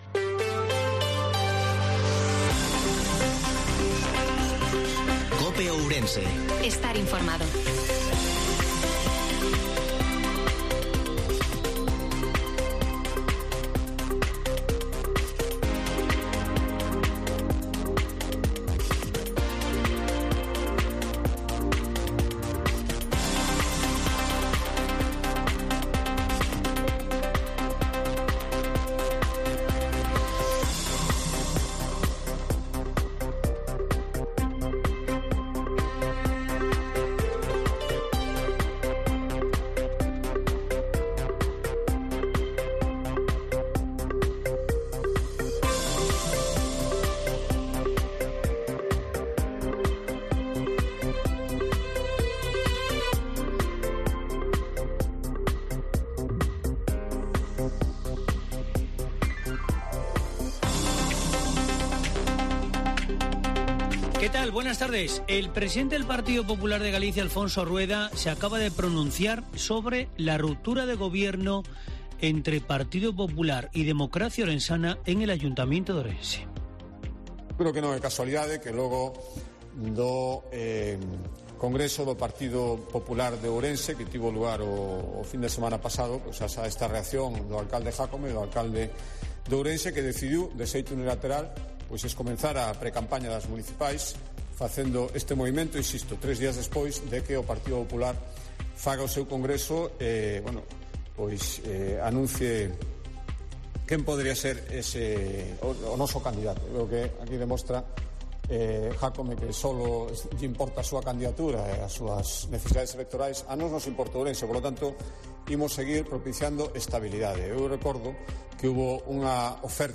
INFORMATIVO MEDIODIA COPE OURENSE-27/07/2022